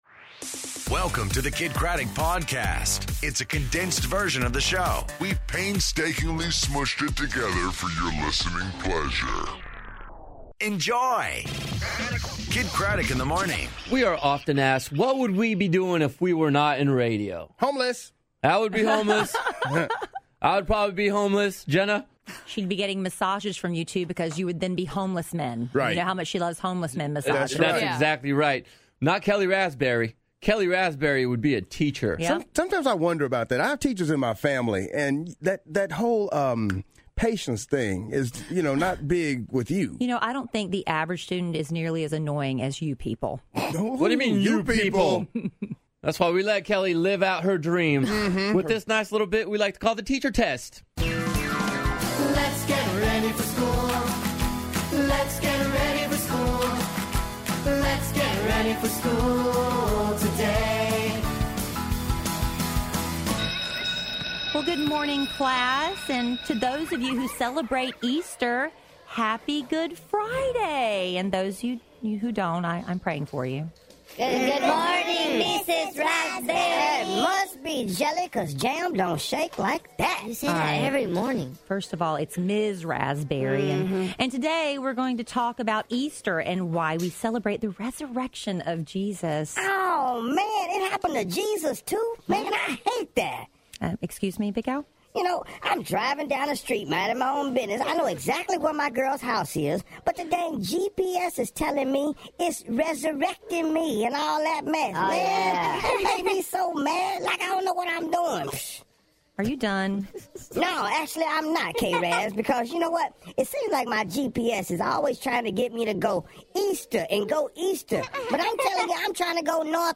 The Teacher Test, Marlon Wayans In Studio, And The Hot Hook Challenge